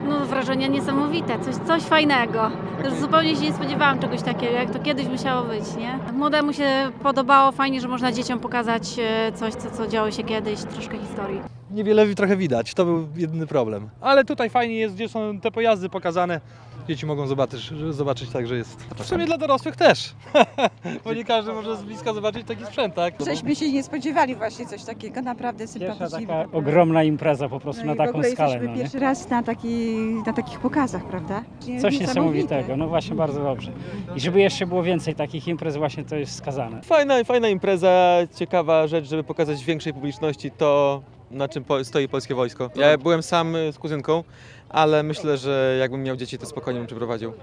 – To wydarzenie, którego nie można przegapić – mówili uczestnicy.